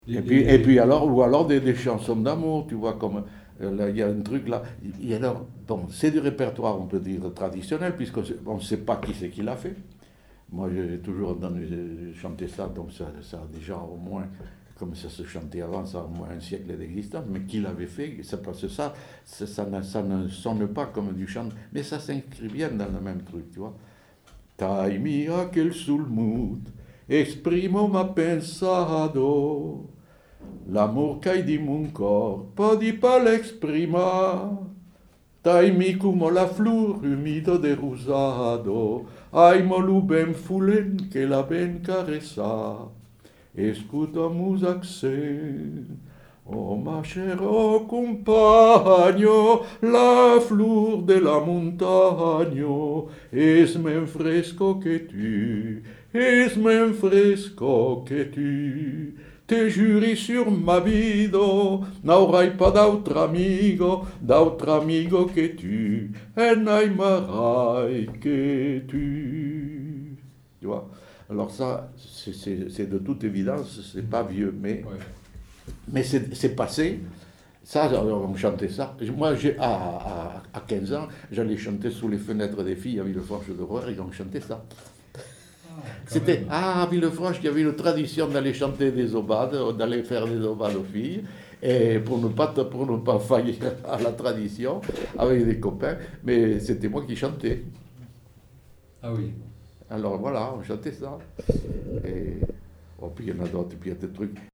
Aire culturelle : Rouergue
Genre : chant
Effectif : 1
Type de voix : voix d'homme
Production du son : chanté
Contextualisation de l'item : chanté pour les aubades